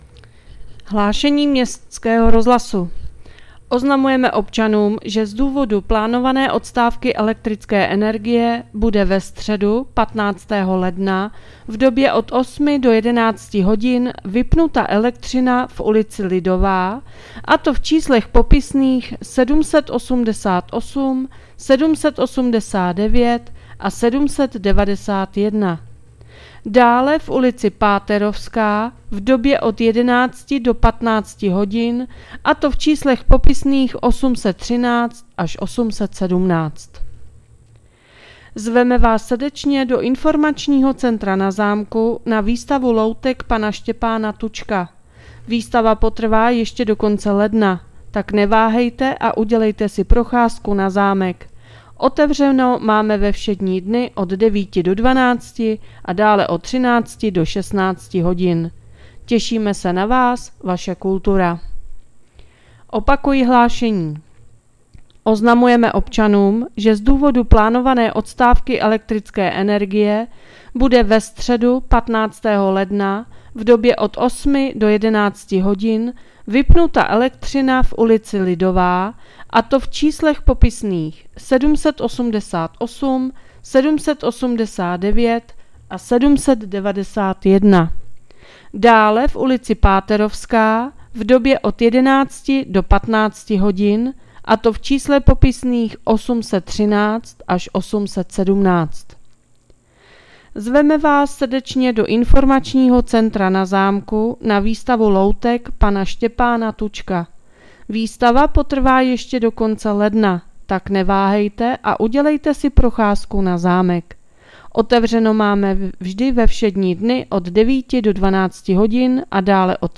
Hlášení městského rozhlasu 13.1.2025